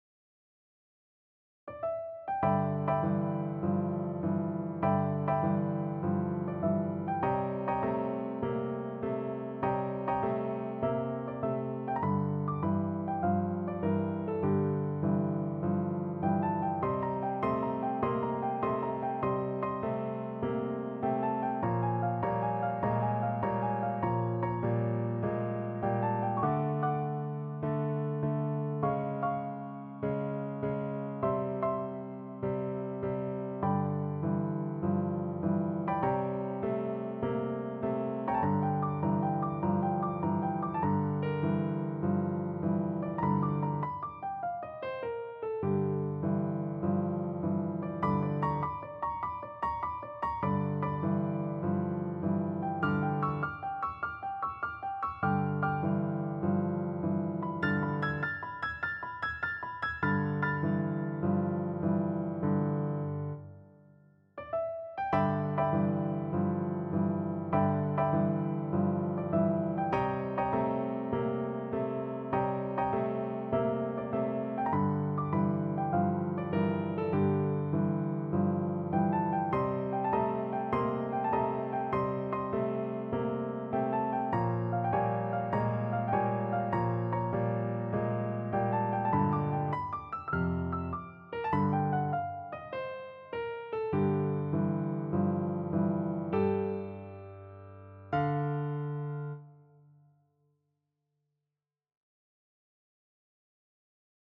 Piano sheet music composed by piano teacher.